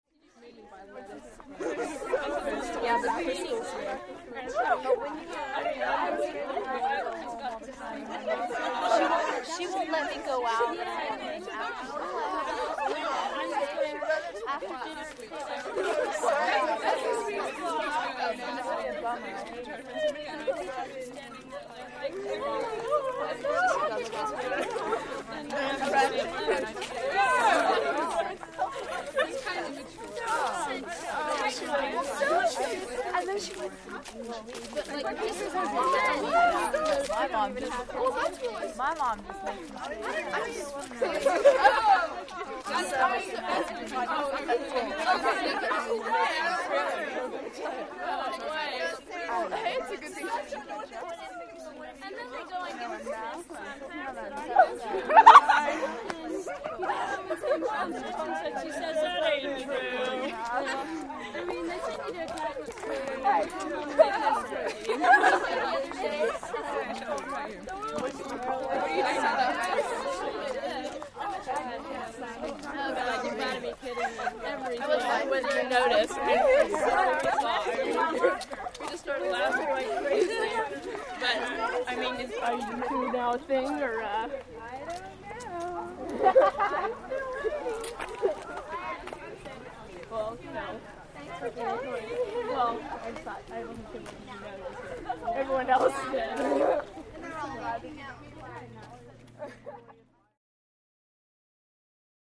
Teenage Girls Walking By, W Talking Gossip.